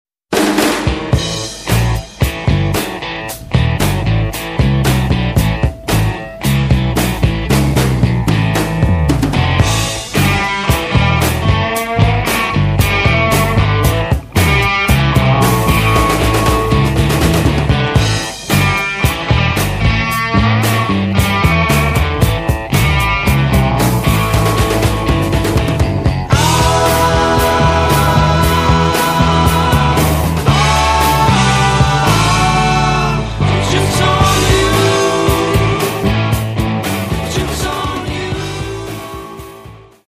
ドラム
ギター
ベース